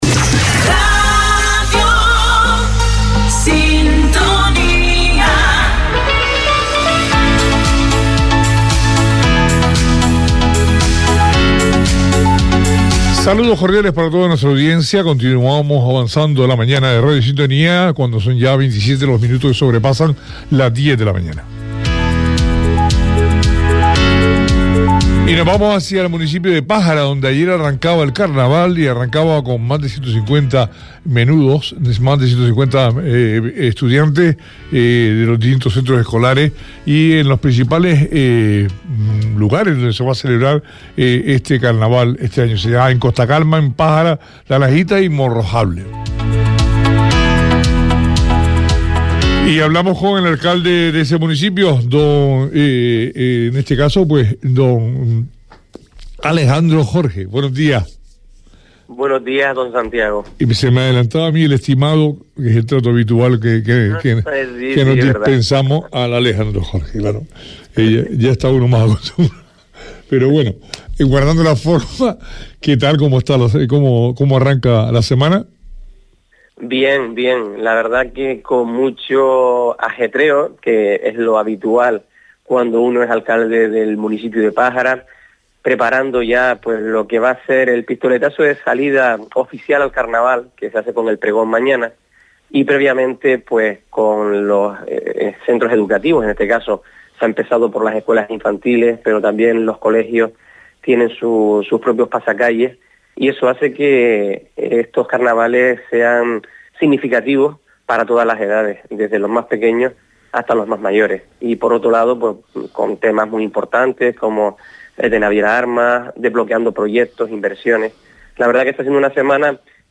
Alejandro Jorge, alcalde de Pájara intervino esta mañana en la radio para valorar distintos temas vinculados a su municipio, entre ellos, la retirada de las conexiones marítimas entre Morro Jable y Gran Canaria de Naviera Armas o las dificultades para alquilar viviendas en la zona sur de la isla.
Entrevista a Alejandro Jorge, alcalde de Pájara – 21.02.24